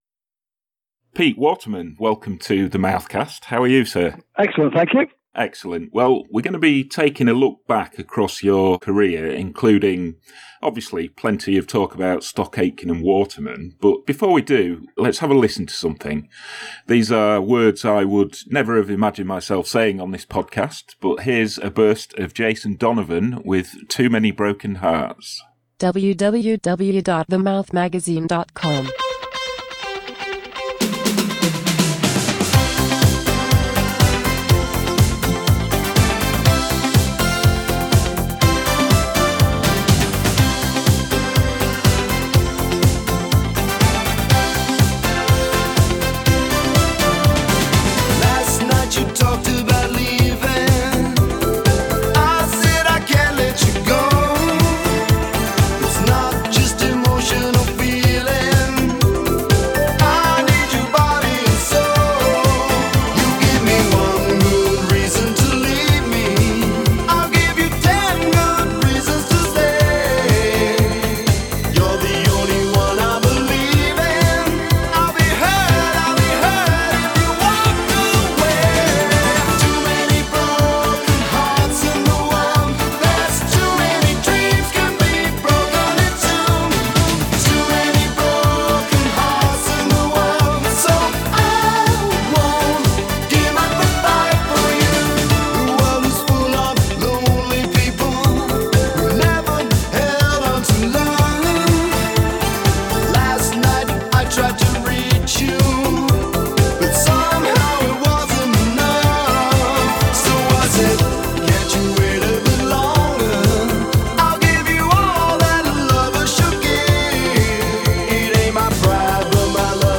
In this new edition of The Mouthcast, Pete Waterman looks back at the Stock, Aitken & Waterman set-up, and recalls a visit from Morrissey when Pete Burns was in the studio recording Dead Or Alive’s classic YOU SPIN ME ROUND (LIKE A RECORD). He also discusses his early days as a DJ in Coventry and some-time manager of The Specials, plus the current state of the music industry and the impact of digital technology on the singles / albums markets.